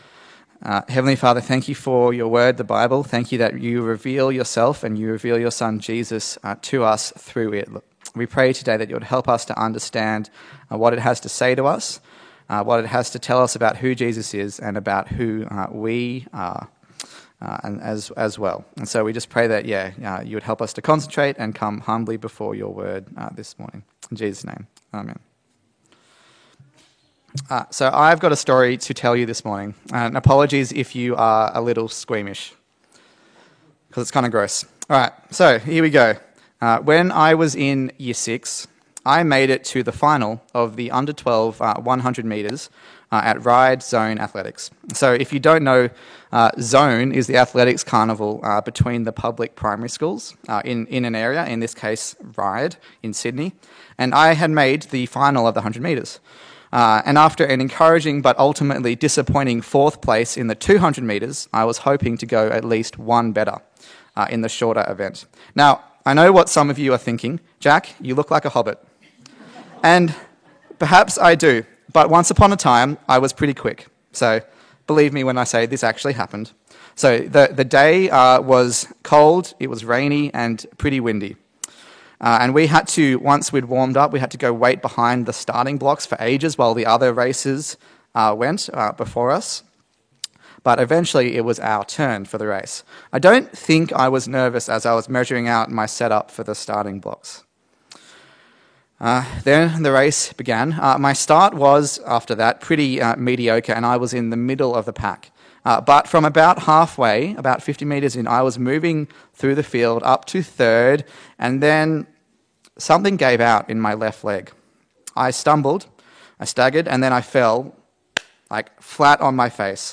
A sermon in the series on the Gospel of Luke
Luke Passage: Luke 5:17-32 Service Type: Sunday Service